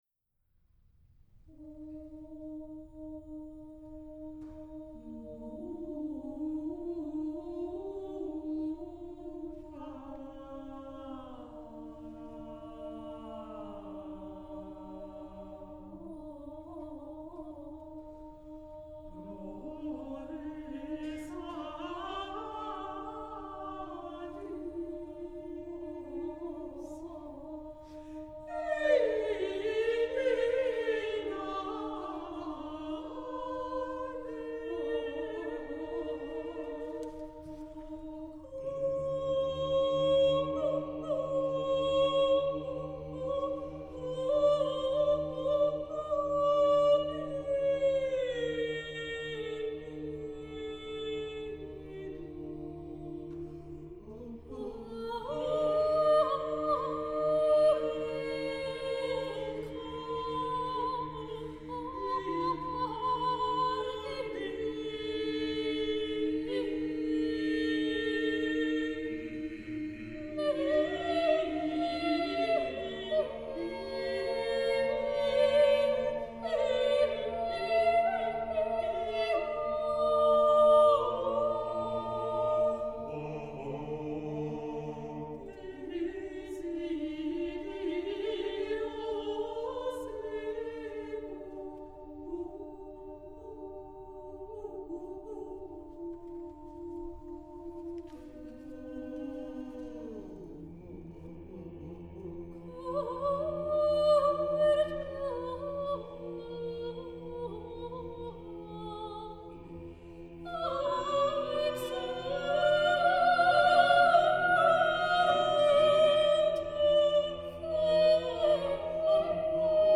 Vocal
a capella choir (13′)